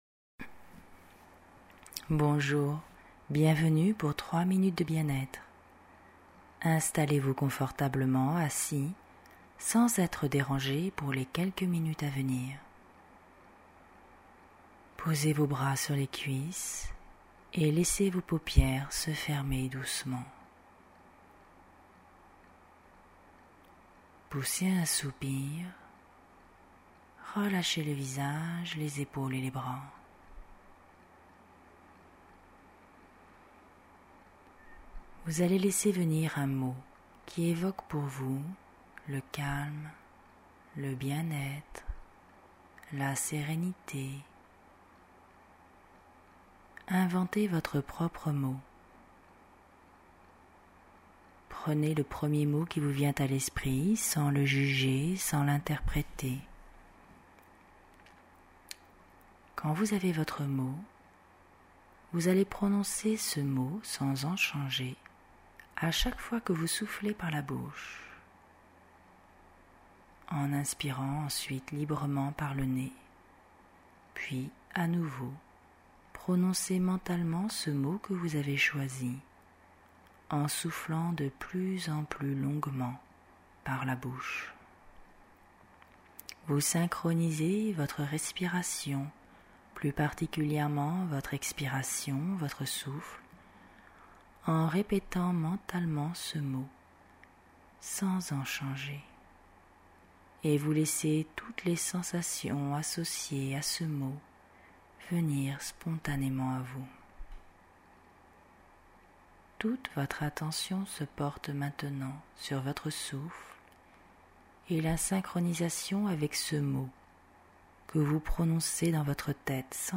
Genre : sophro
respiration-synchronique-sur-mot.mp3